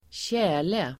Ladda ner uttalet
tjäle substantiv, frost in the ground Uttal: [²tj'ä:le] Böjningar: tjälen Synonymer: frusen mark Definition: frusen mark Sammansättningar: tjäl|skada (frost damage), tjäl|lossning (thawing of the ground)